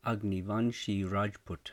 Click to hear the pronunciation of this People Group.
Pray for the Sri Lanka Moor in Sri Lanka pronounced: moorh The Moors of Sri Lanka trace their ancestry to Arab traders who settled in the island nation sometime between the eighth and fifteenth centuries.